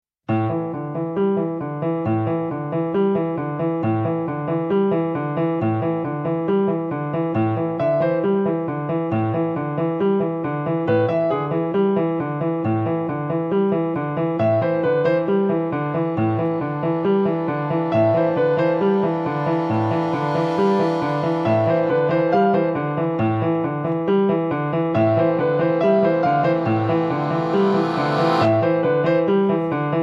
revisited in jazz ways
accordion
piano